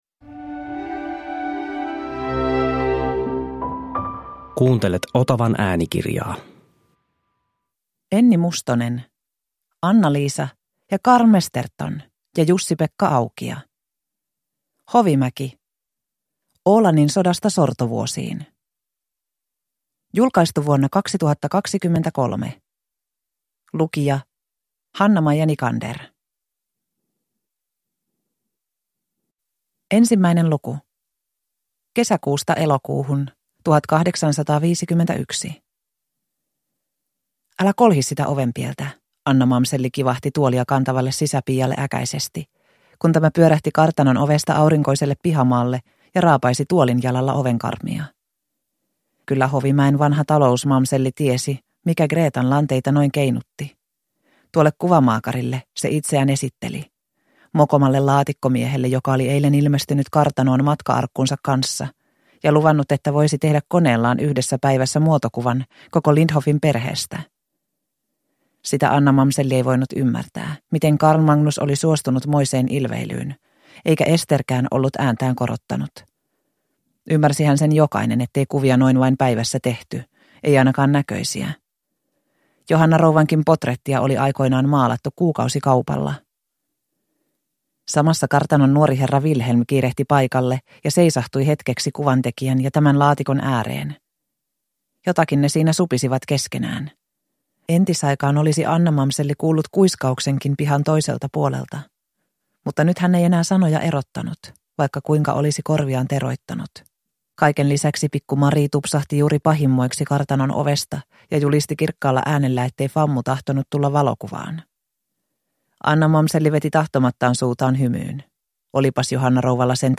Oolannin sodasta sortovuosiin – Ljudbok